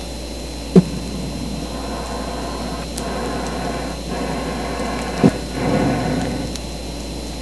Harbor Seal announcing control of territory.wav